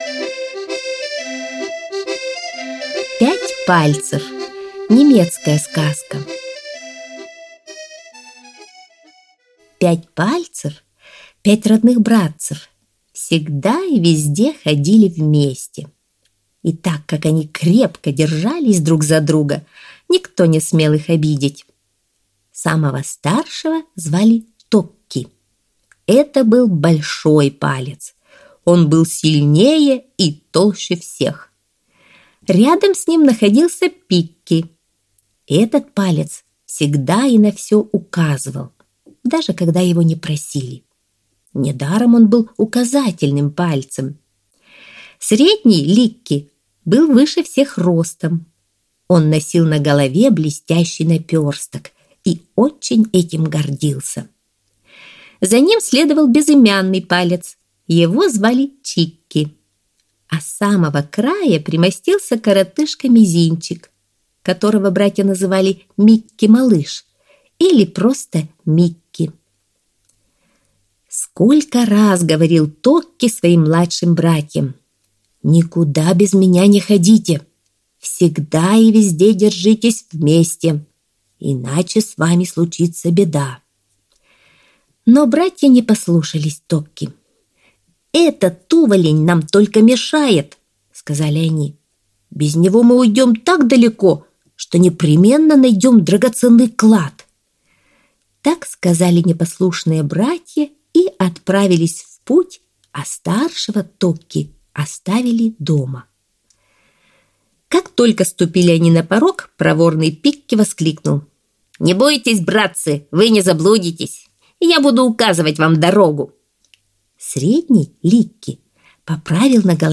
Пять пальцев - немецкая аудиосказка - слушать онлайн